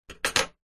Металлические медицинские ножницы лежат на столе 5